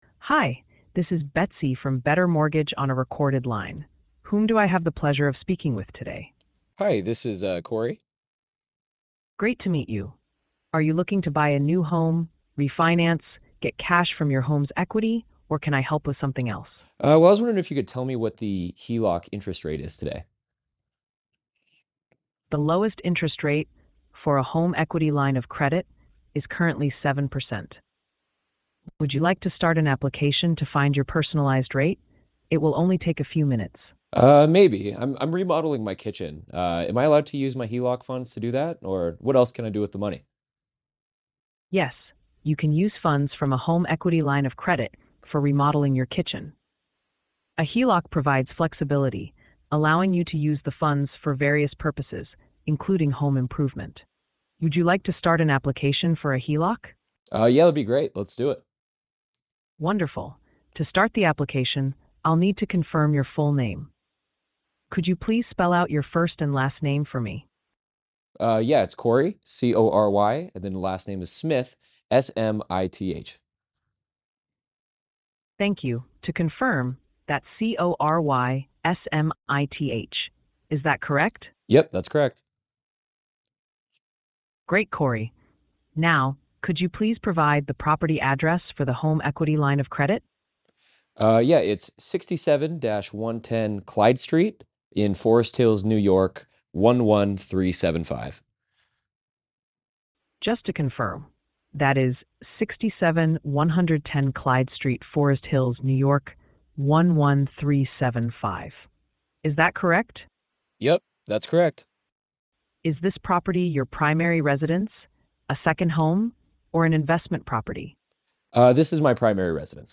Betsy AI Agent Demo - Rate Lock
Betsy-AI-Agent-Demo-Rate-Lock.mp3